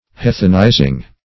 & vb. n. Heathenizing (-[imac]`z[i^]ng).] To render heathen or heathenish.
heathenizing.mp3